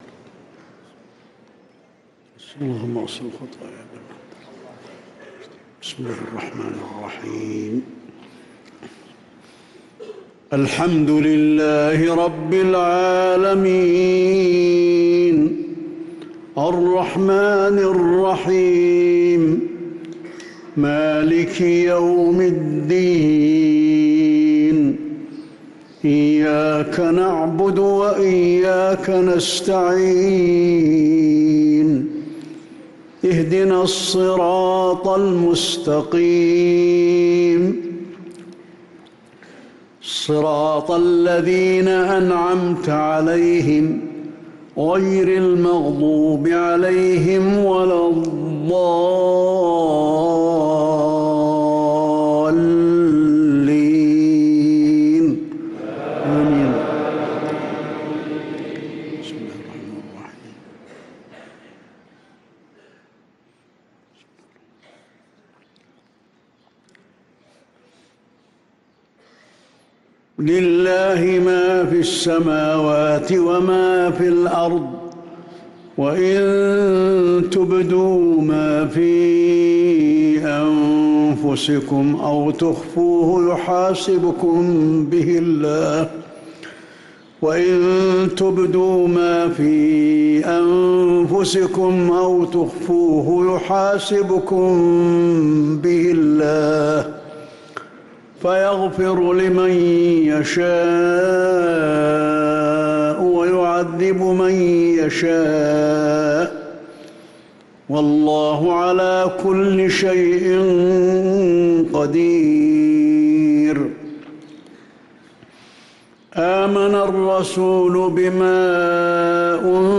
صلاة المغرب للقارئ علي الحذيفي 12 شعبان 1445 هـ